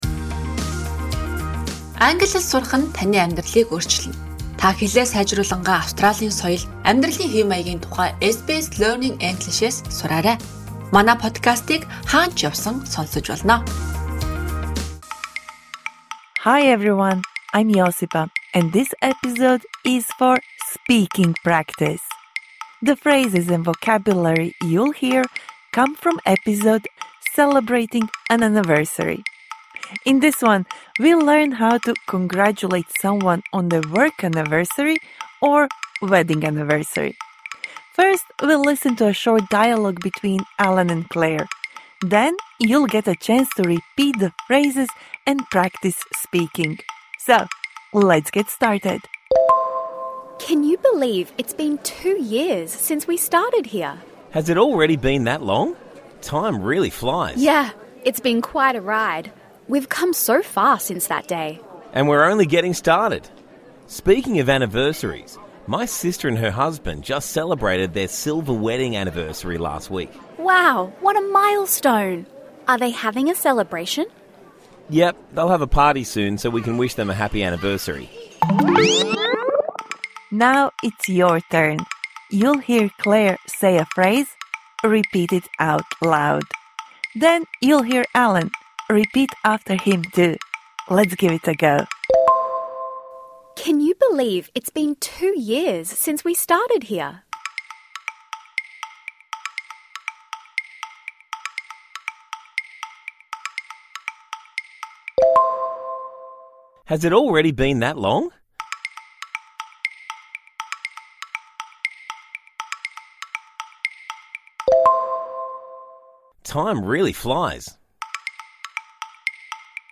This bonus episode provides interactive speaking practice for the words and phrases you learnt in Episode #88 Celebrating an anniversary (Med).